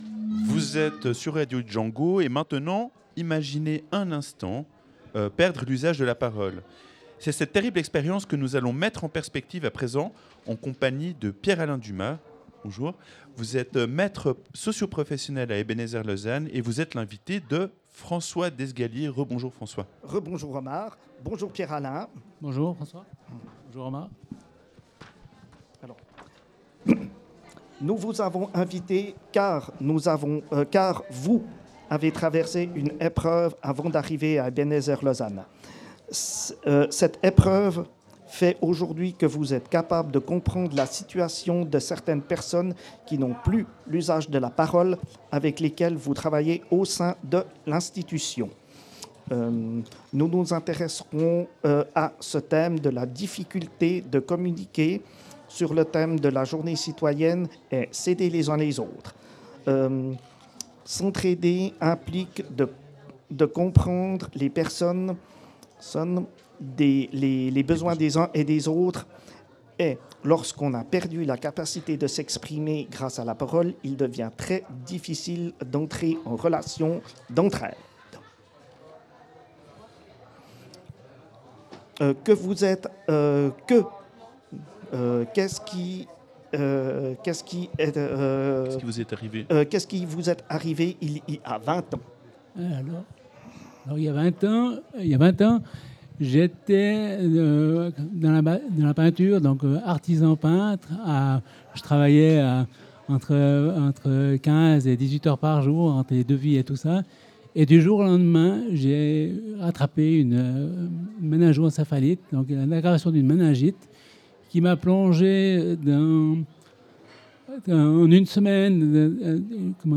Deux émissions ont été préparées tout l’été avec des résidents.
Citoyennete2_ITW2_non-expression.mp3